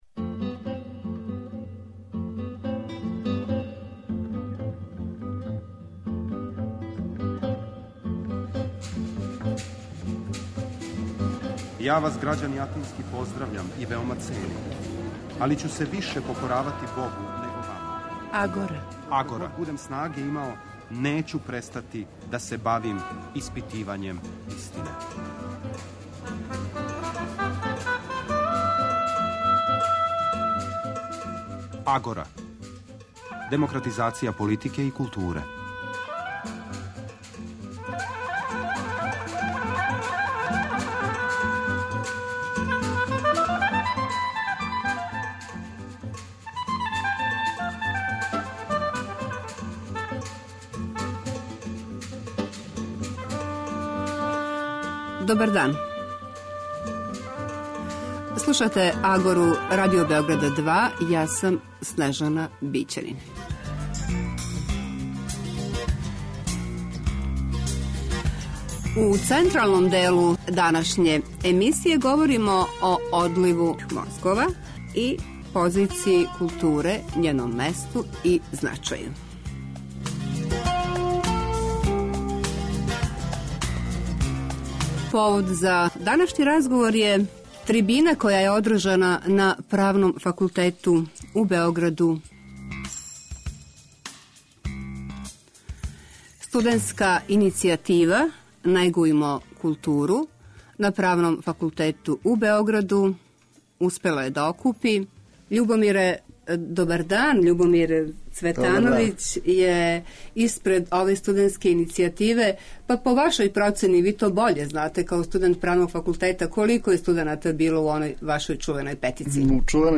Други сат радио-магазина - директно из Књажевца
Други сат Агорe ове суботе реализујемо директно из Књажевца, где је у току 55. Фестивал културе младих Србије. У разговорима са представницима локалне самоуправе, организаторима Фестивала, али и са младим Књажевчанима, проверићемо колико ова манифестација обогаћује слику овог краја и колико је истрајала у мисији да буде посвећена првенствено стваралаштву младих.